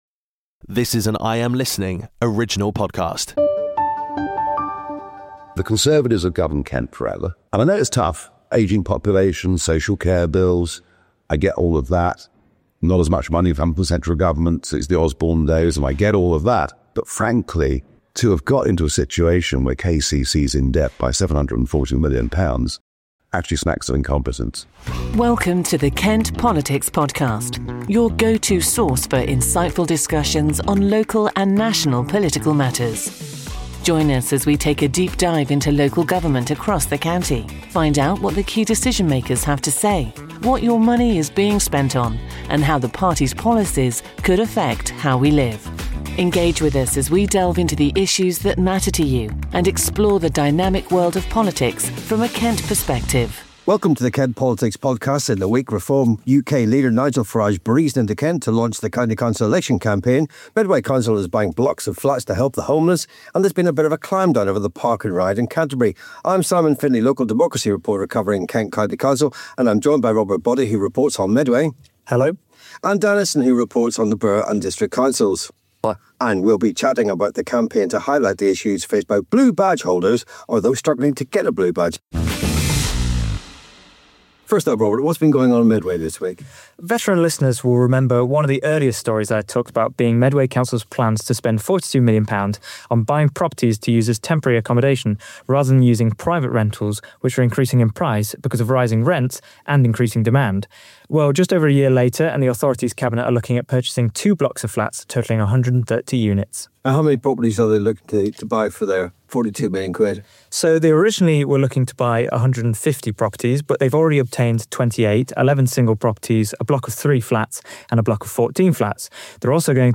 In this week's episode of the Kent Politics Podcast, we delve into pressing local issues with a focus on political dynamics across Kent. From Medway's ambitious housing plans to Canterbury's park and ride debacle, our hosts dissect how these developments impact residents' daily lives.